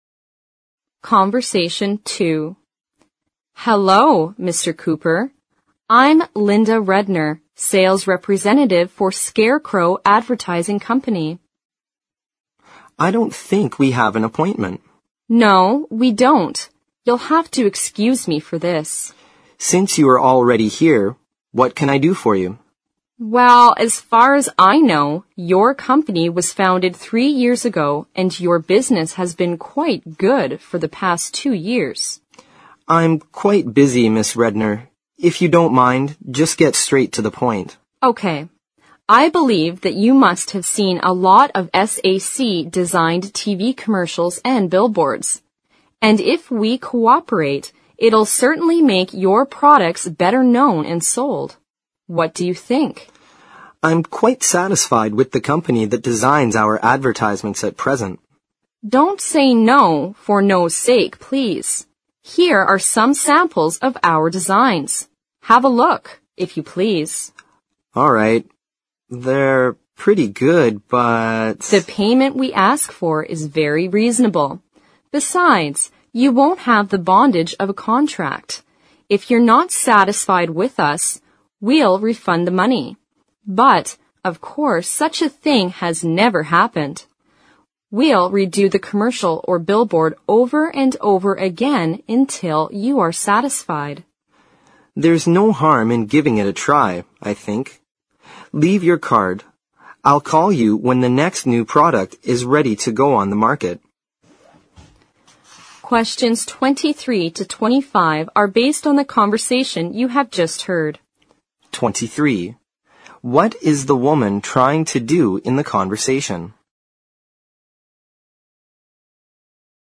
Conversation Two